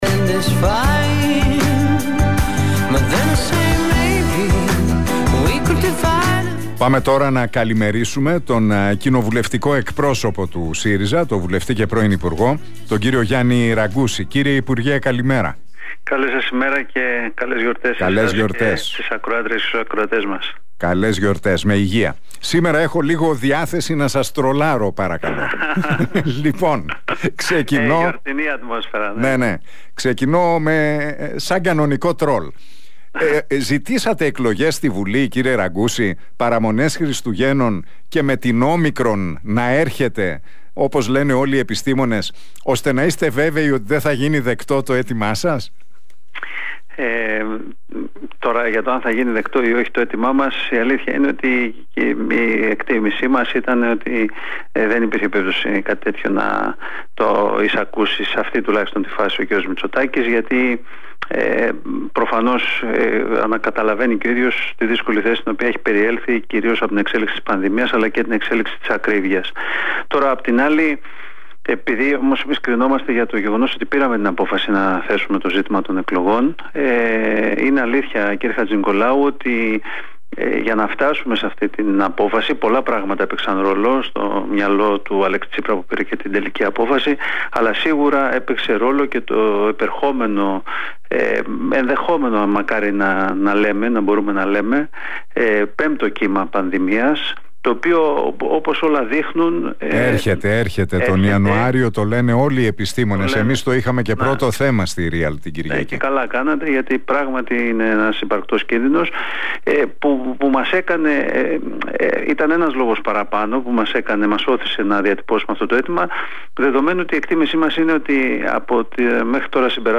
Ραγκούσης στον Realfm 97,8: Βλέπουμε ένα πέμπτο κύμα και την κάκιστη διαχείριση της κυβέρνησης